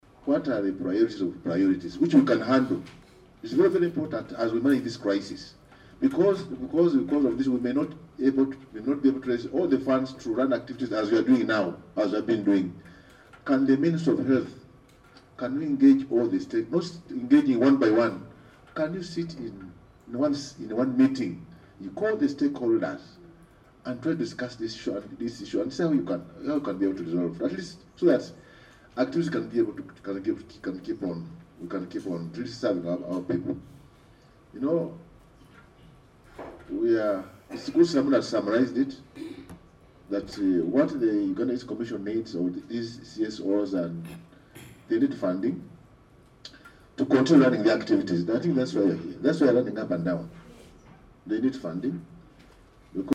Officials from the two organisations made this appeal on Tuesday, 11 February 2025 while appearing before the Committee on Health chaired by Hon. Joseph Ruyonga.